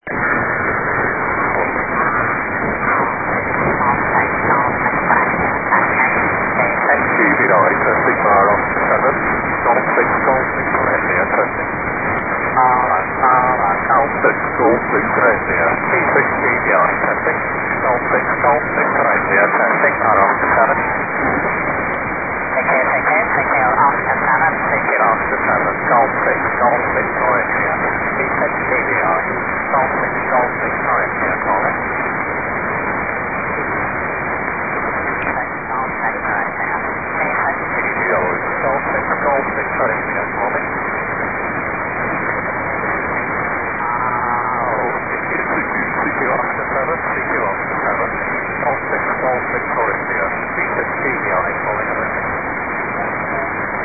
This "bird" has a dual-band linear transponder on-board, with an uplink on 70cm (LSB) and downlink on 2m (USB), so I have rigged up a pair of beams to try to operate through it.
I had practised operating through satellites back in the mid-1990s, so it didn't take me long to re-master the ancient art of Doppler-shift tuning and get my signal through -
satpass1.mp3